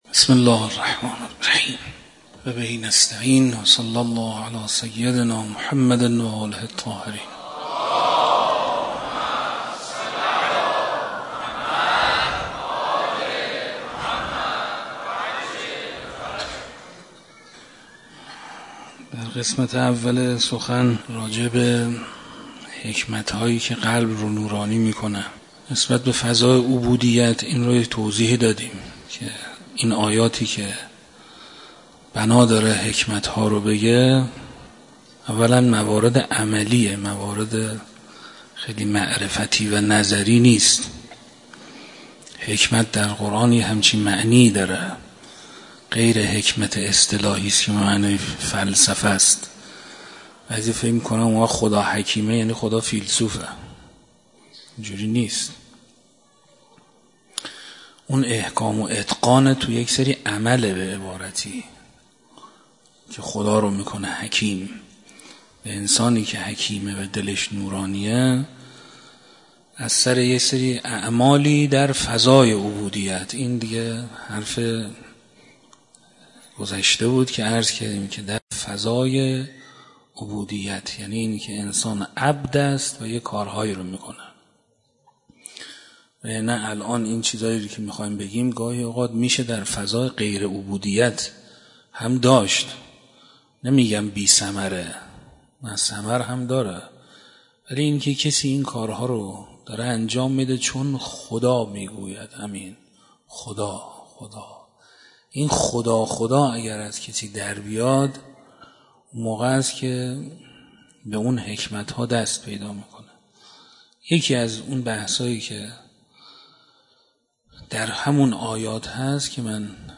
شب تاسوعا محرم 96 - مسجد دانشگاه صنعتی شریف